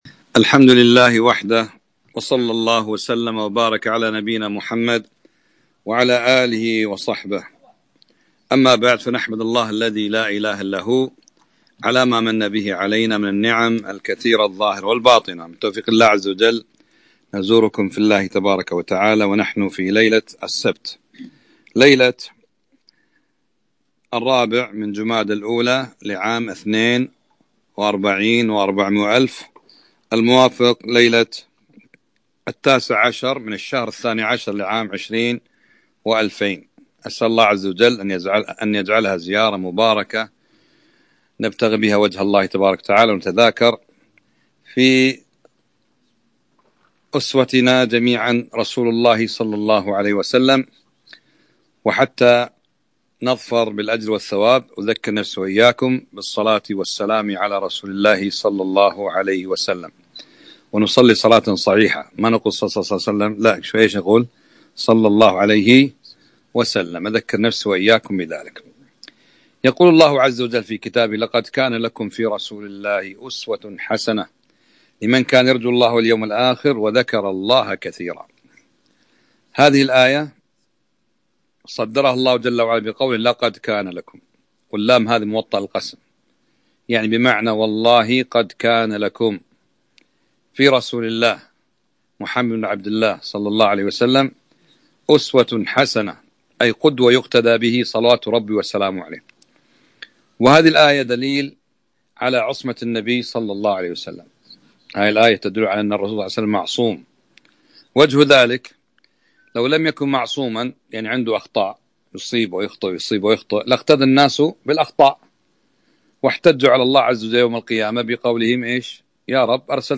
محاضرة - لقد كان لكم في رسول الله أسوة حسنة 3-5-1442